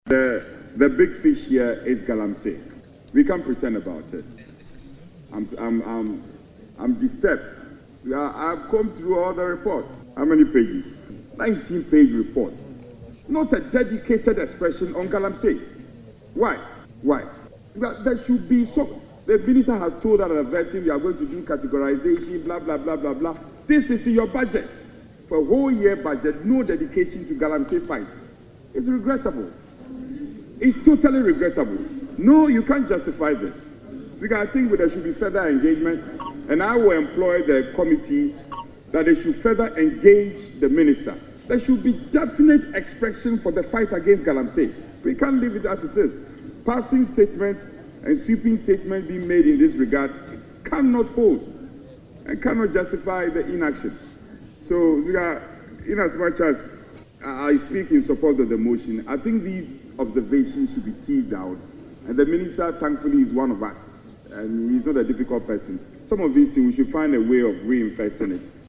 Speaking during the consideration of budgetary estimates for the Lands Ministry, Frank Annoh-Dompreh wondered why the government would claim it is serious in the fight against illegal mining without setting aside dedicated funding for this fight.